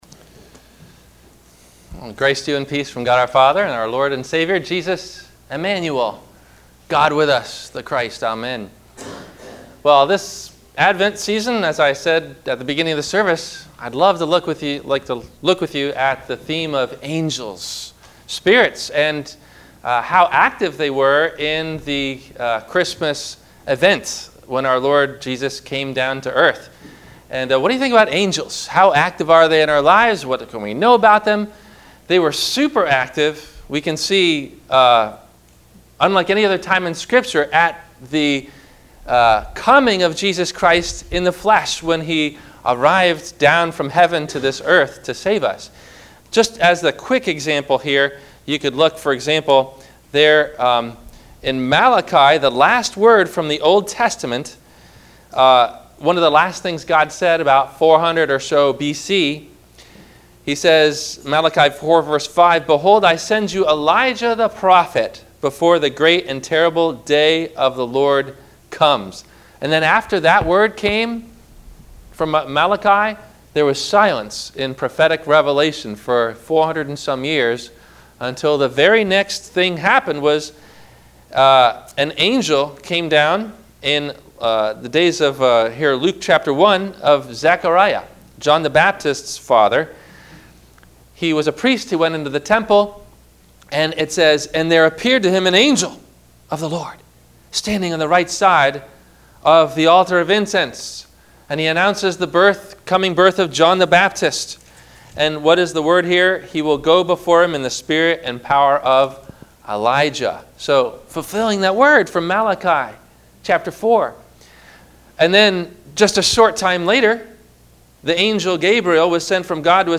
What Can We Know About Angels? - Advent Wednesday 1 - Sermon - November 30 2016 - Christ Lutheran Cape Canaveral